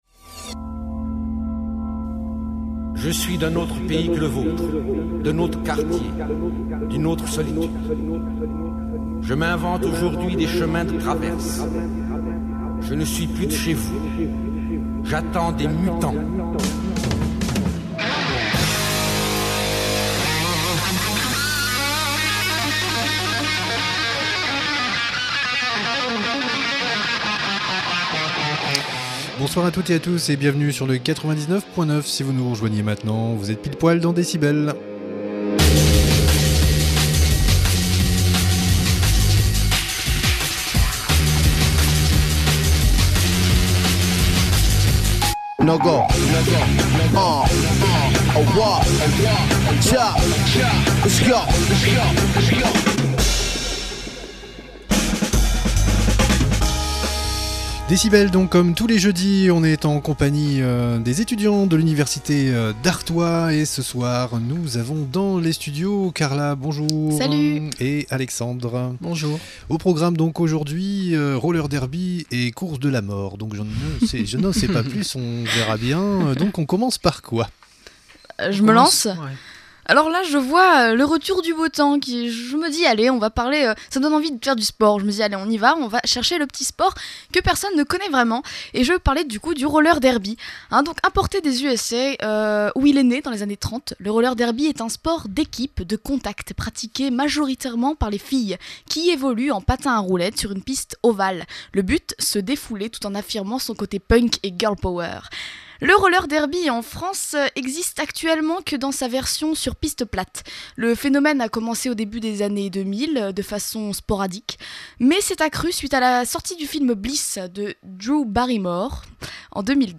L’émission Décibels, tous les jeudis, est consacrée à l’Atelier Radio des étudiants de l’Université d’Artois d’Arras, de 18h30 à 19h.